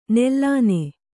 ♪ nellāne